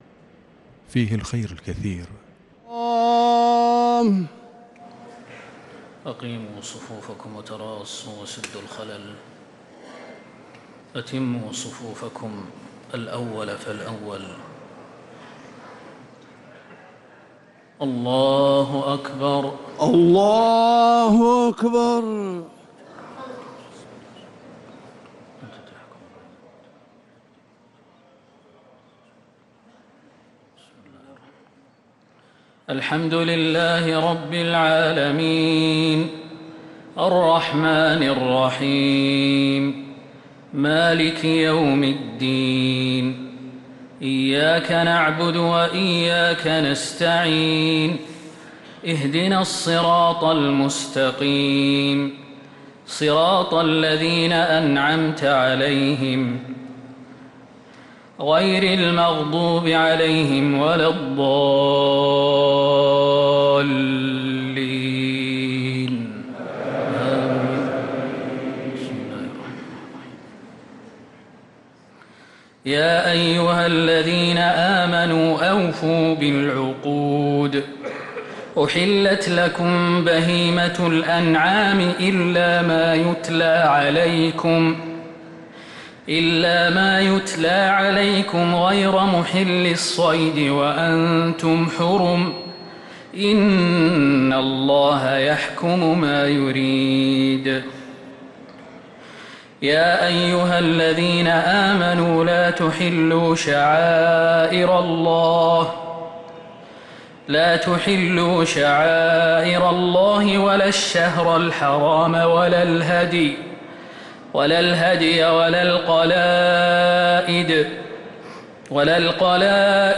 صلاة التراويح ليلة 8 رمضان 1445 للقارئ خالد المهنا - الثلاث التسليمات الأولى صلاة التراويح